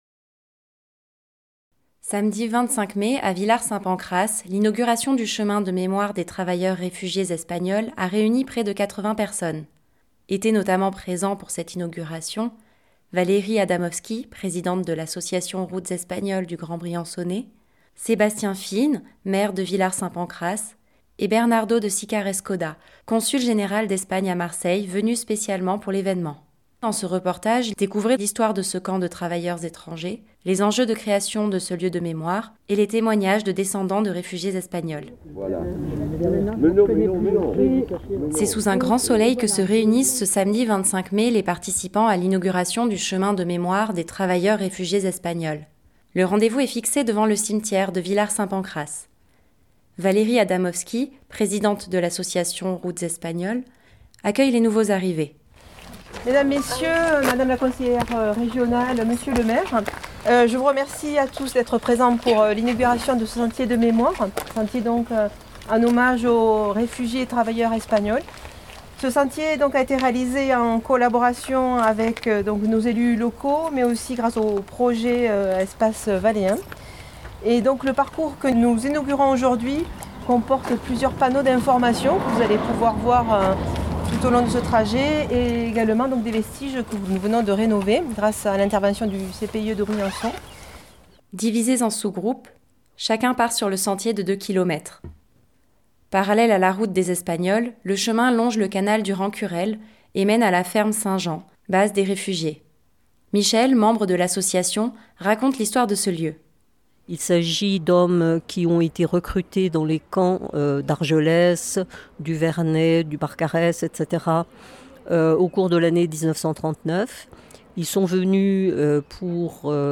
Découvrez dans ce reportage l'histoire de ce camp de travailleurs étrangers, les enjeux de la création de ce lieu de mémoire, et les témoignages de deux briançonnais, descendants de réfugiés espagnols. Musique enregistrée lors de la cérémonie, avec Cello au Sommet.